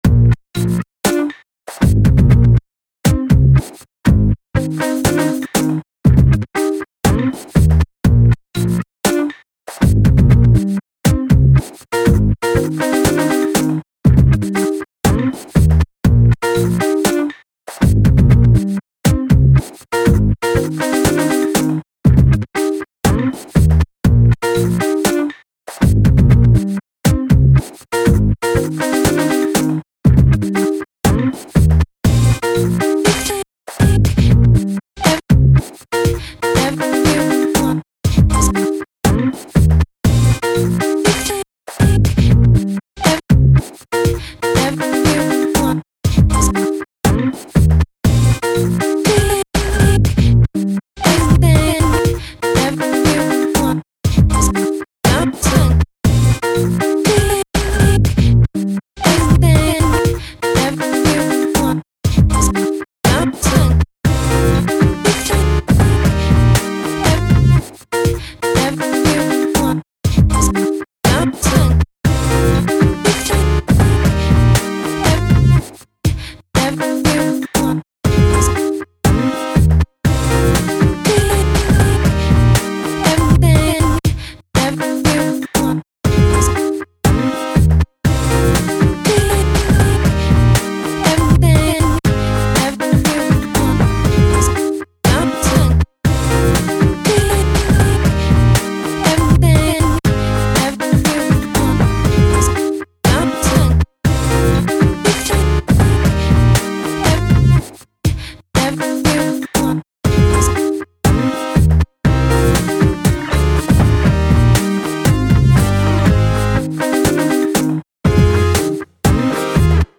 Electronic music is an endless world